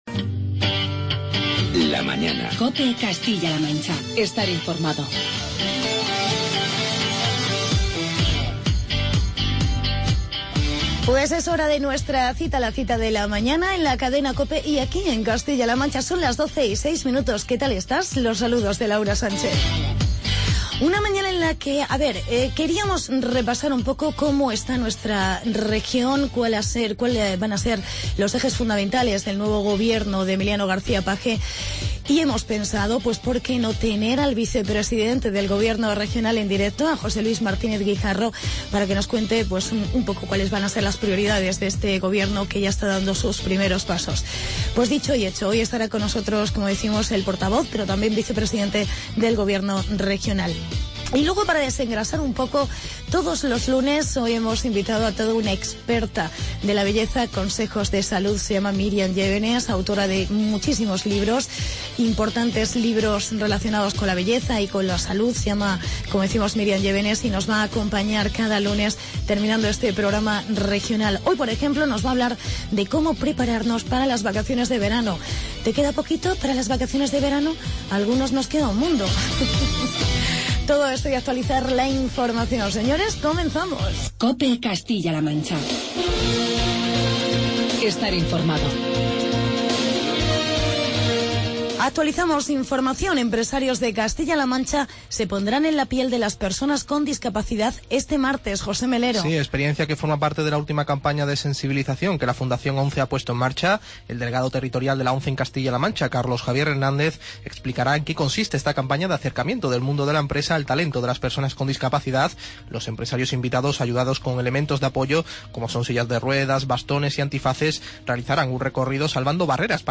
Entrevista con el vicepresidente CLM, José Luis Martínez Guijarro